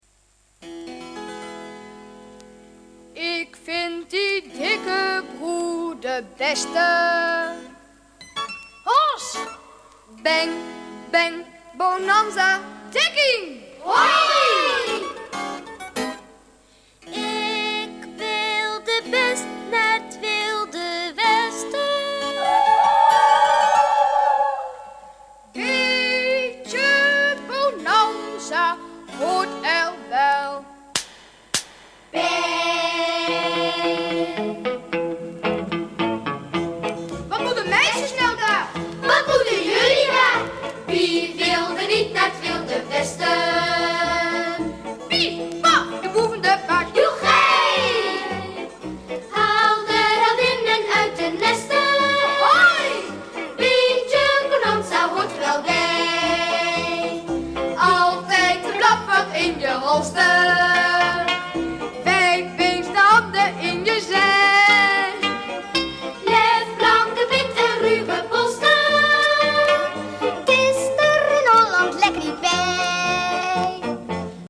Liedjes